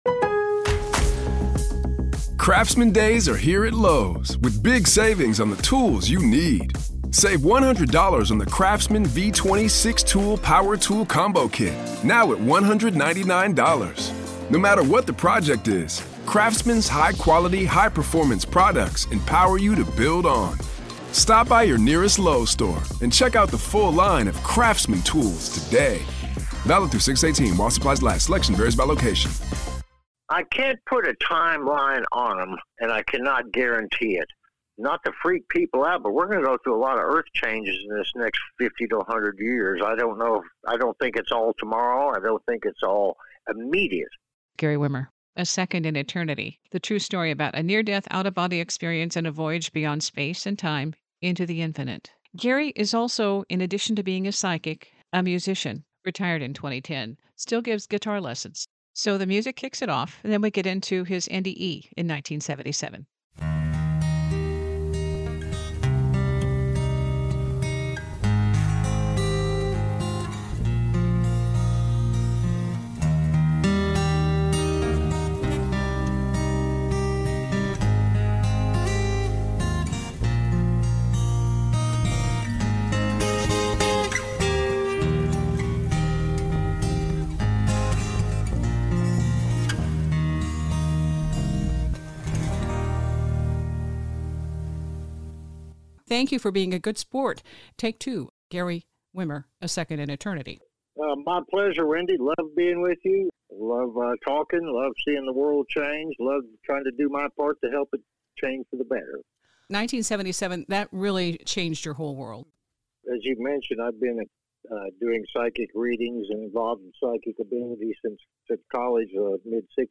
Archived refresh. 2 music segments. The intro, and at the close of the interview.